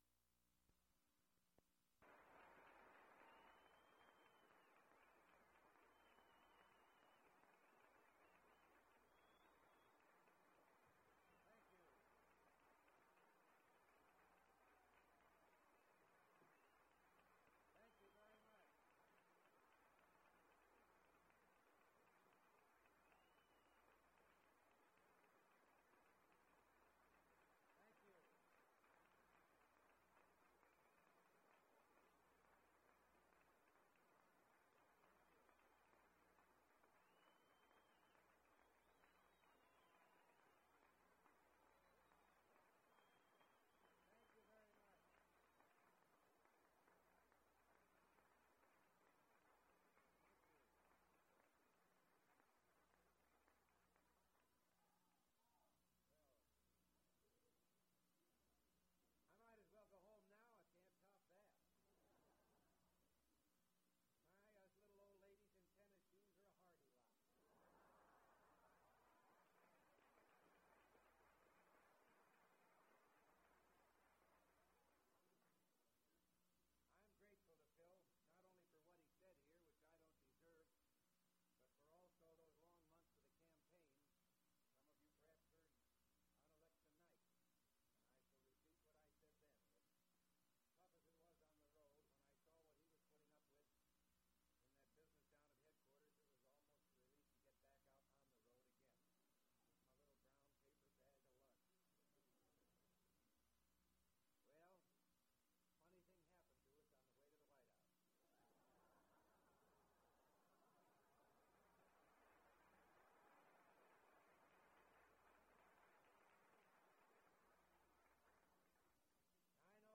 Ronald Reagan’s speech after Goldwater defeat at L.A. County Young Republicans Meeting, Wilshire Hotel
11/1964, Reel to Reel Audio Format (CD preservation copy).